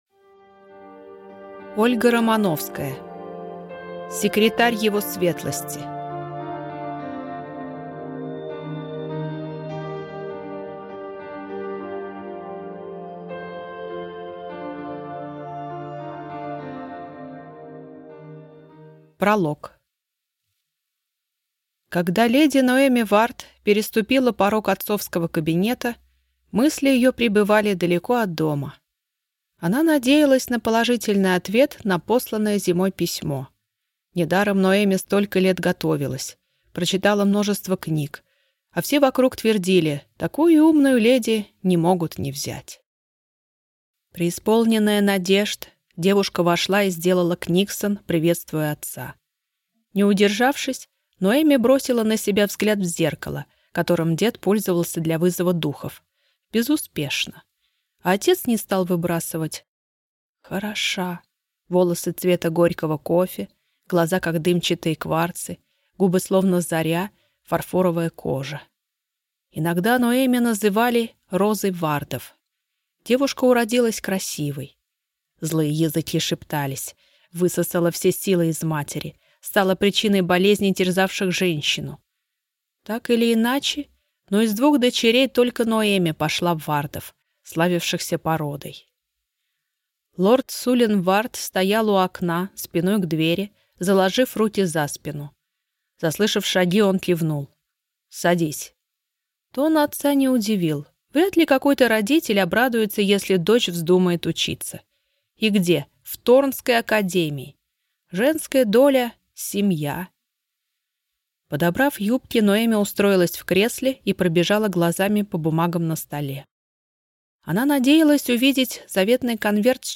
Аудиокнига Секретарь его светлости | Библиотека аудиокниг